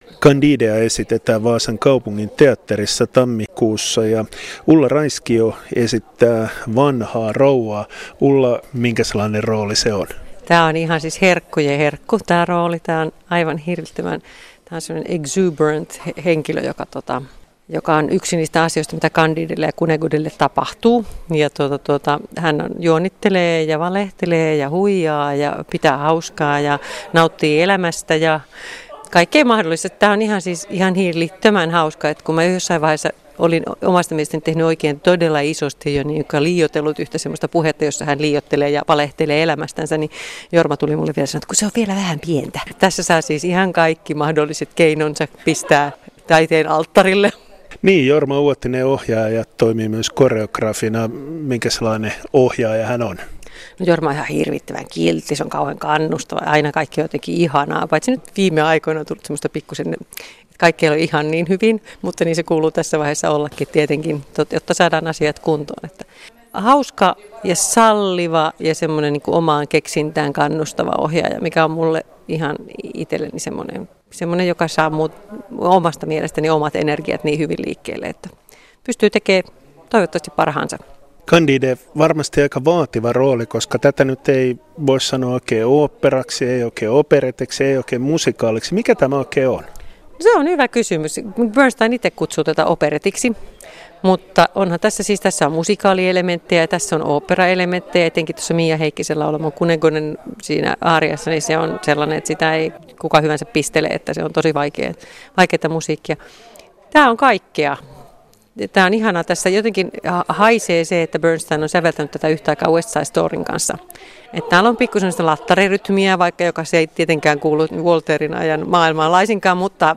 haastattelussa on oopperan tekijöitä